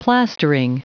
Prononciation du mot plastering en anglais (fichier audio)
Prononciation du mot : plastering